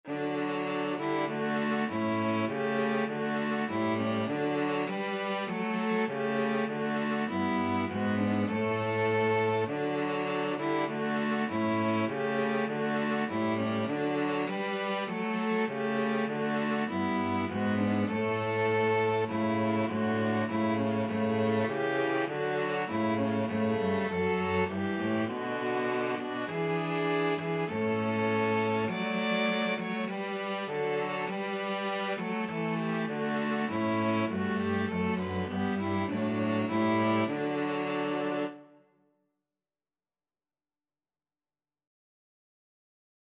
ViolinViolaCello
4/4 (View more 4/4 Music)
Classical (View more Classical String trio Music)